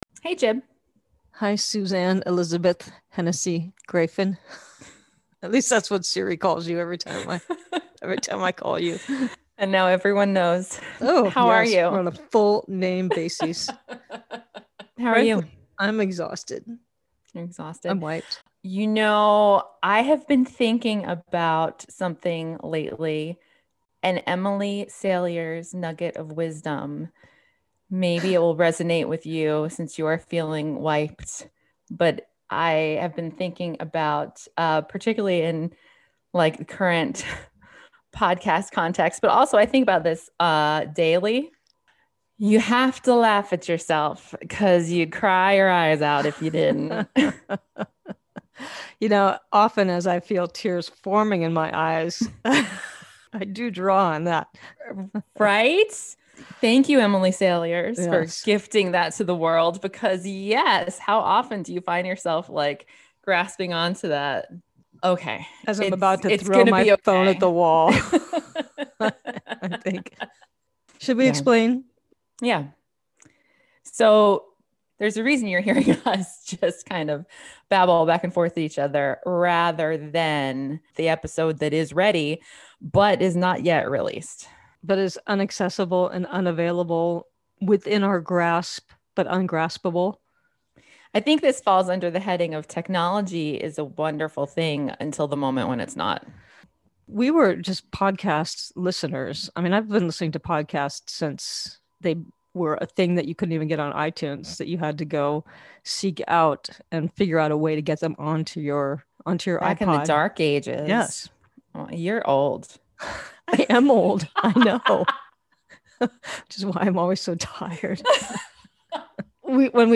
(captured from webcast)